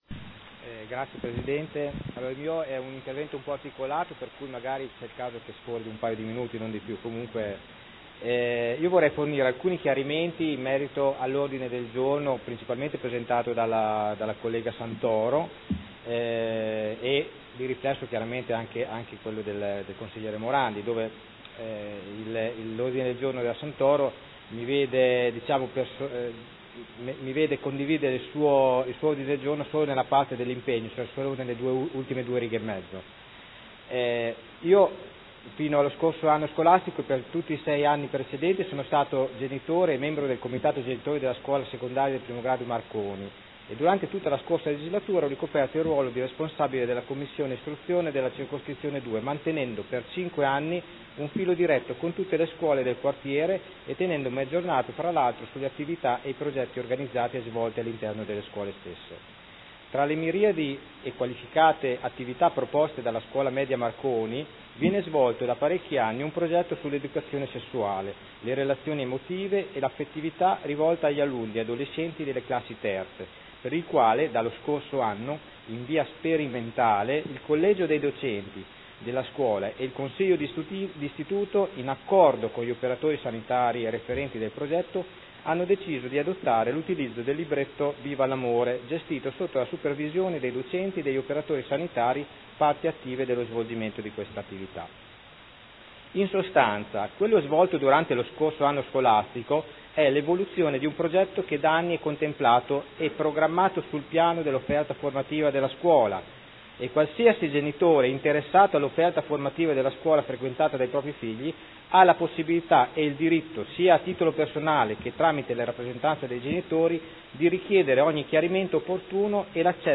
Dibattito sugli ordini del giorno. 17, 18, 21